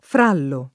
frallo [ fr # llo ] → fra lo